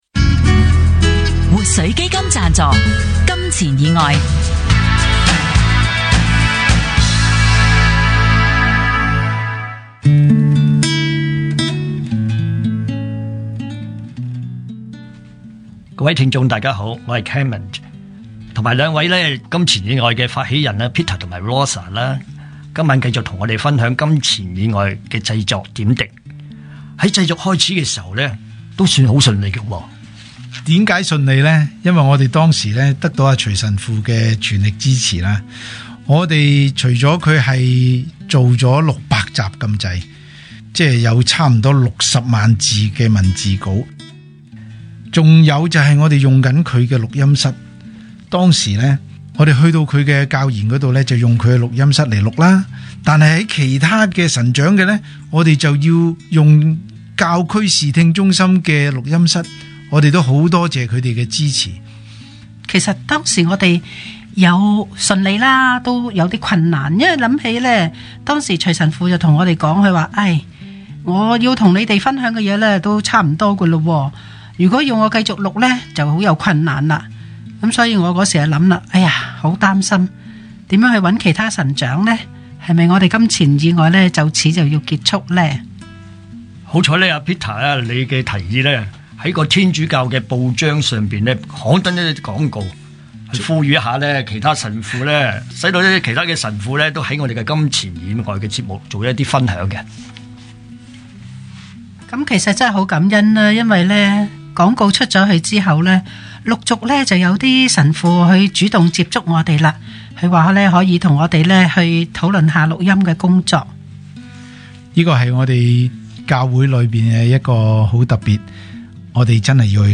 電台：FM104新城財經台
天主教信仰福傳廣播《金錢以外》